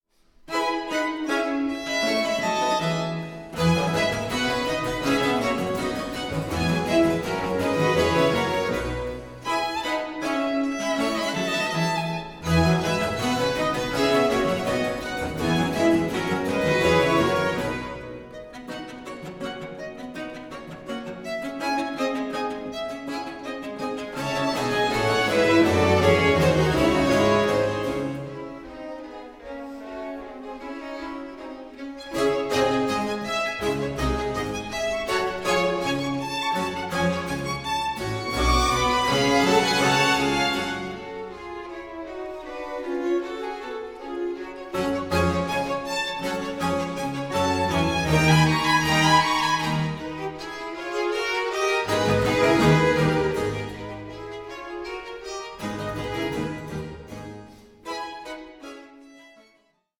Concerto grosso in F Major, Op. 6 No. 6